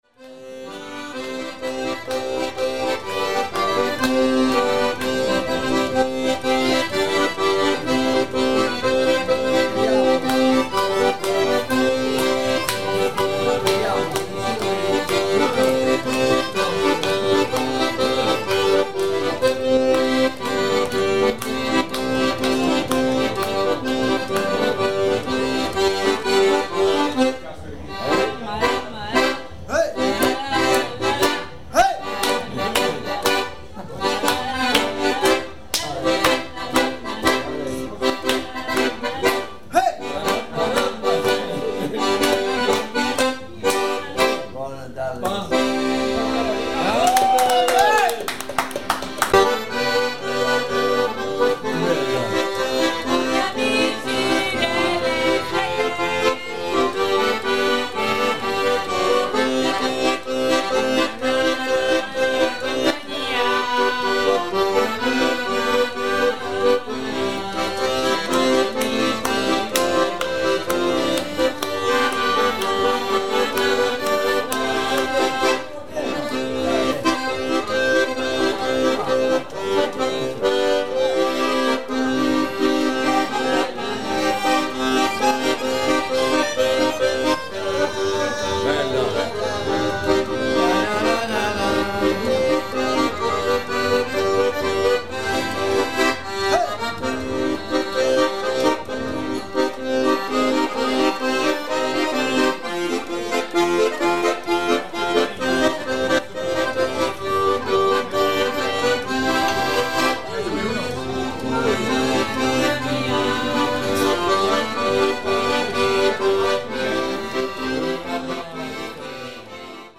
Accordian at Aristo's bar - Barga Vecchia Barga
19th July 2002 - Accordian and dancing at Aristodemo's bar
accordian.mp3